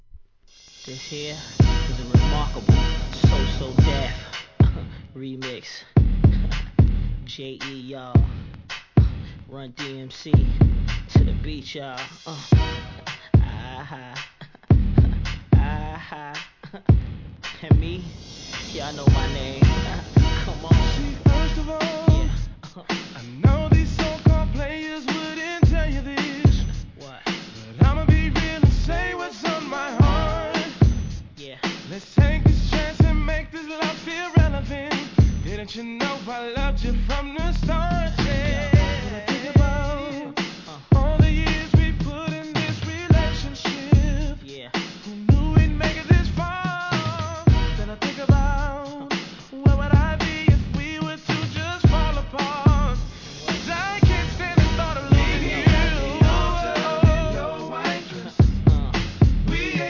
HIP HOP/R&B
彼らの得意とする極上バラード!!